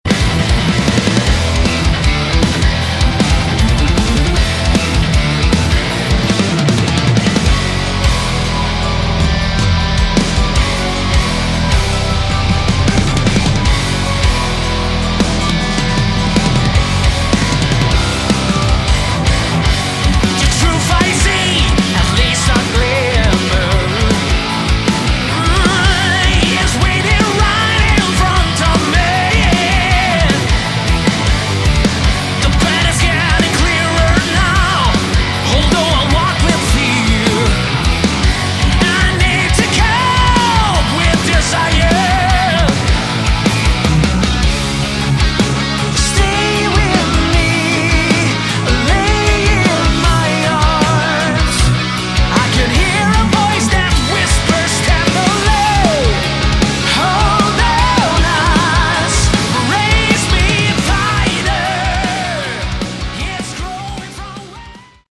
Category: Progressive Rock/Metal
lead vocals
guitars
bass
keyboards
drums